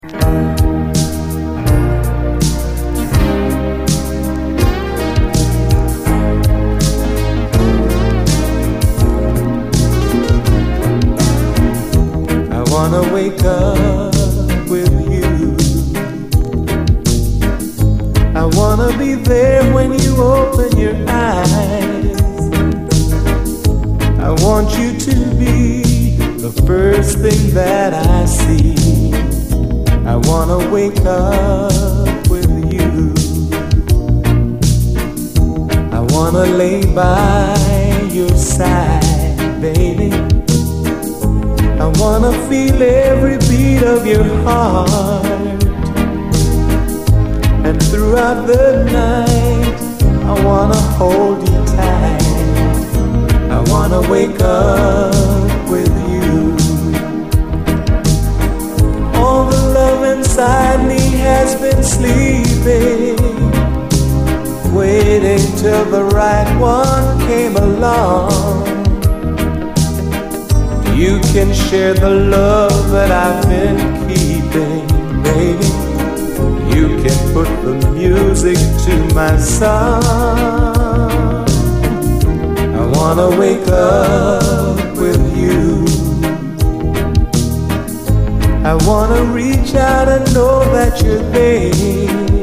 えも言われぬ魅惑のグルーヴが充満！
シンセ・ソロ、ダビーなエフェクト＆展開が素晴らしく、このインスト・ヴァージョンも超最高です！